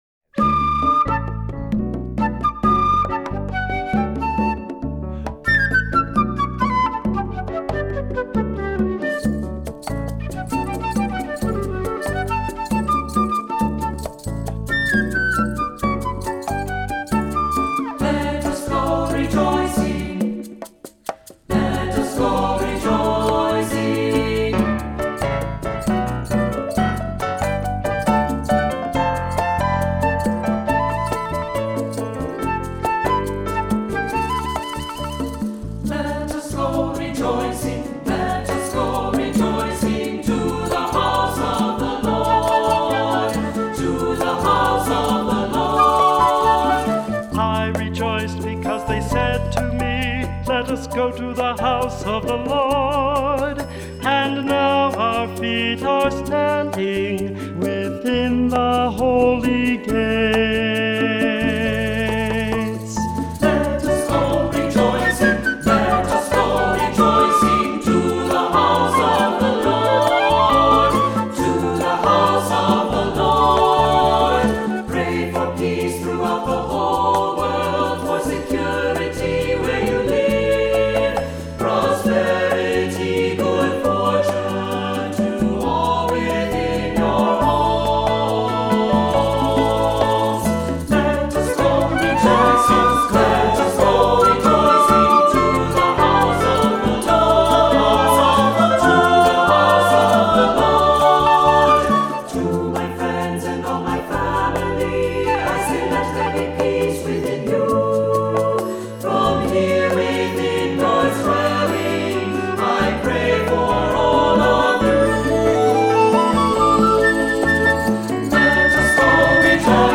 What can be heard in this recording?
Voicing: SATB, cantor